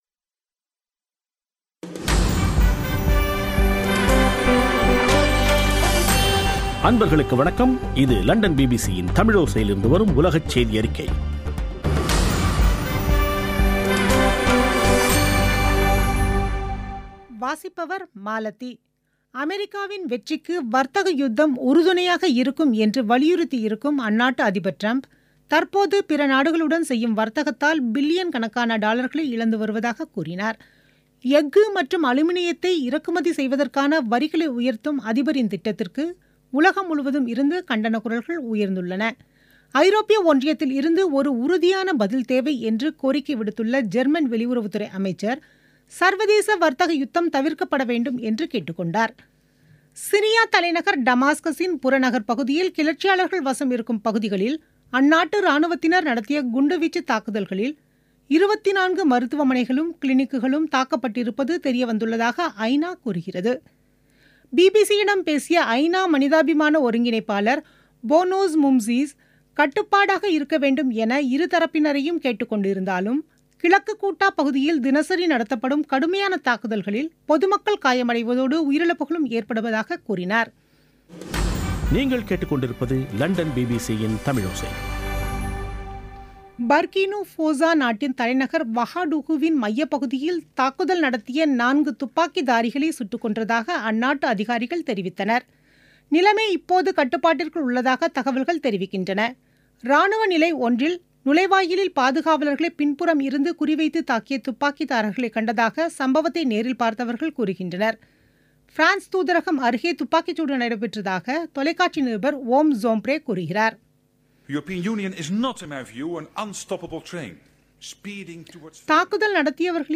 பிபிசி தமிழோசை செய்தியறிக்கை (02/03/2018)